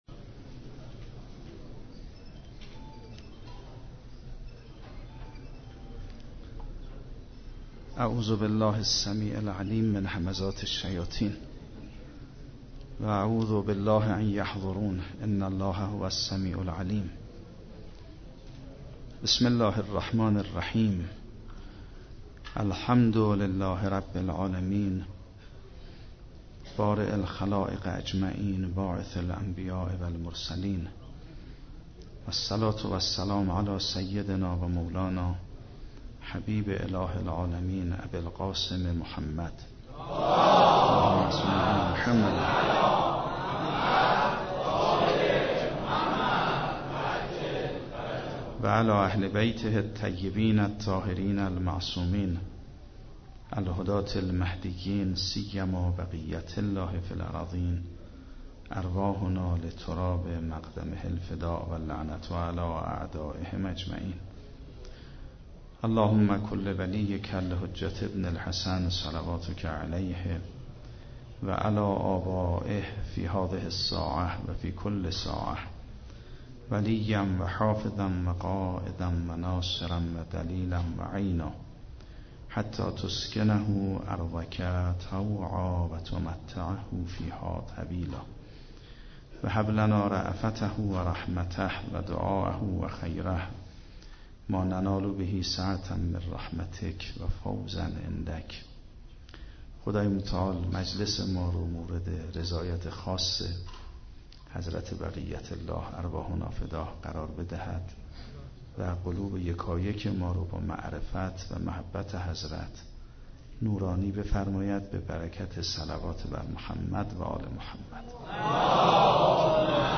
9 دی 96 - کرج - سخنرانی